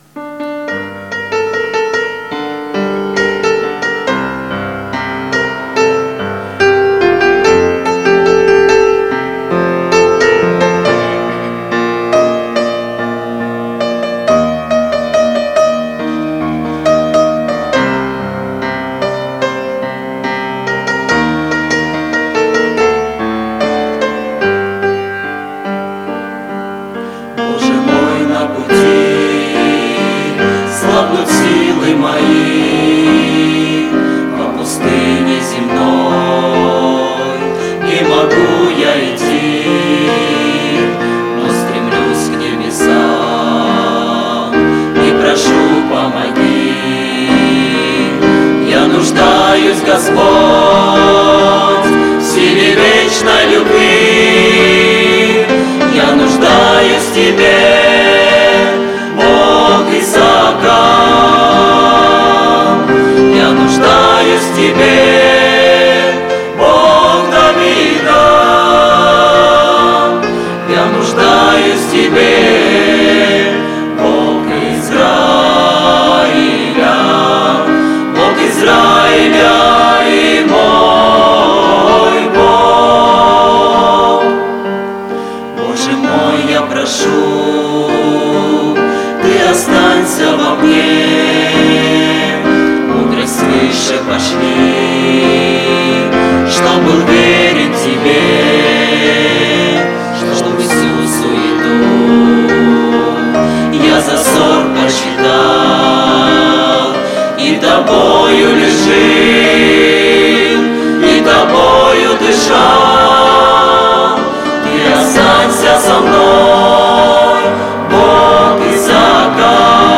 Конференция молодежи ОЦХВЕ Сибири 2019
Я нуждаюсь в Тебе - Молодежь (г. Барнаул) (Пение)[